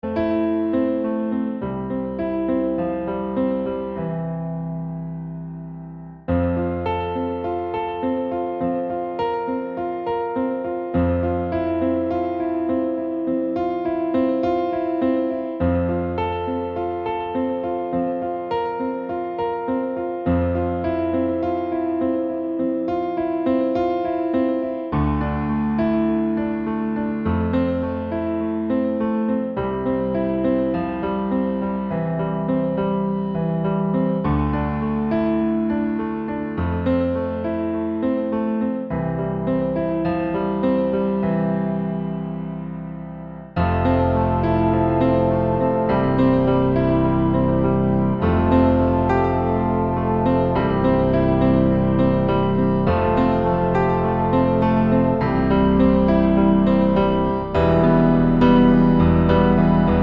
no Backing Vocals Indie / Alternative 2:52 Buy £1.50